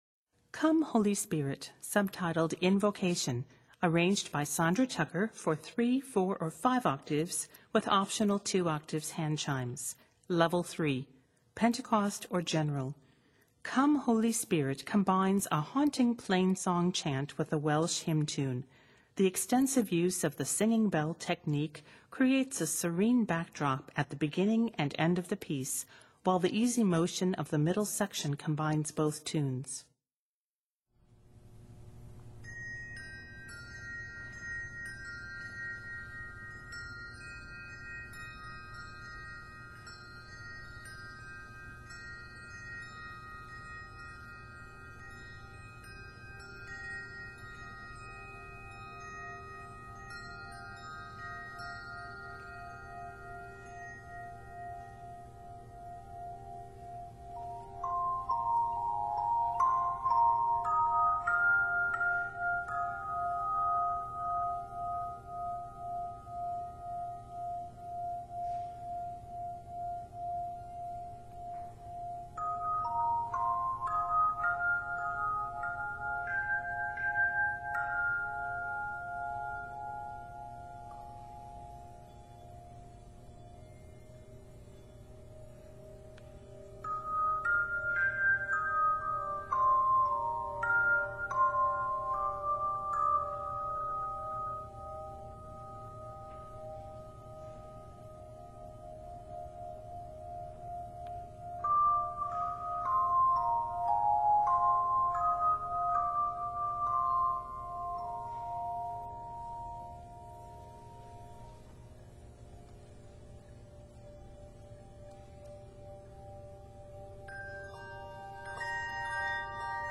These setting is 99 measures and is in the key of c minor.